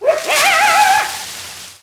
fox.wav